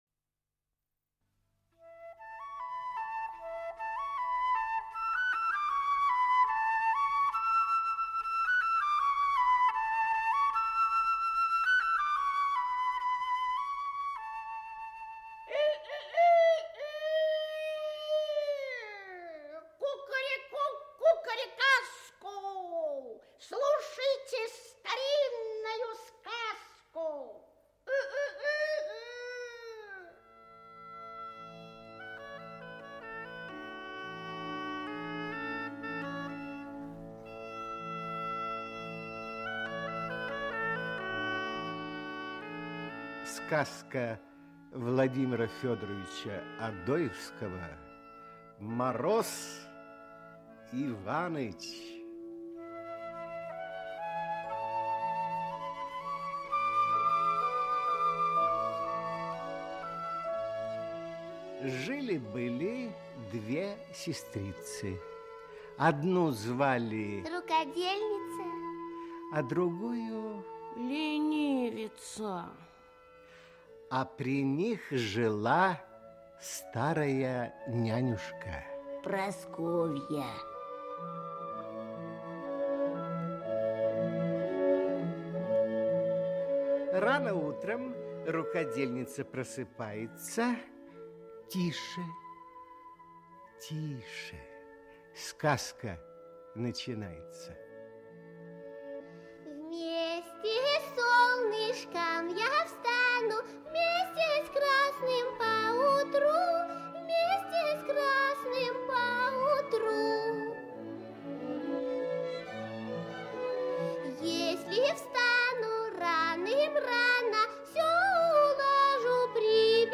Аудиокнига Мороз Иванович | Библиотека аудиокниг